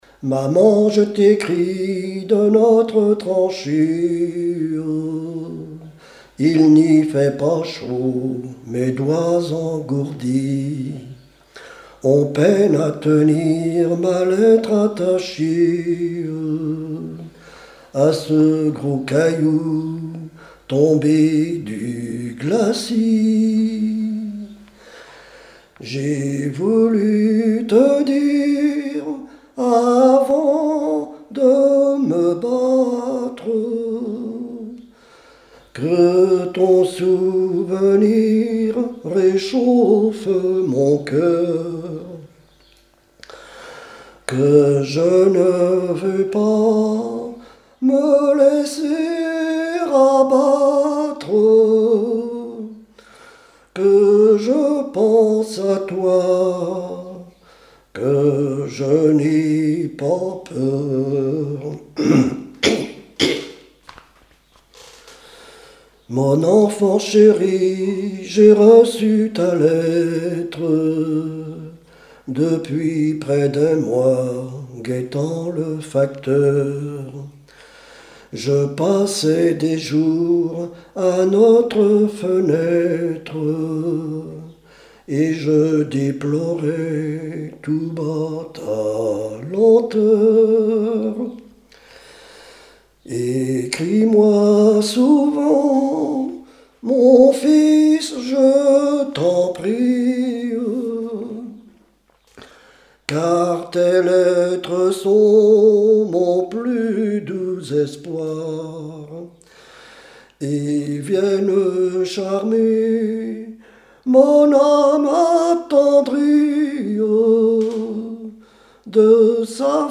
Saint-Maurice-des-Noues
Genre strophique
Témoignages et chansons
Pièce musicale inédite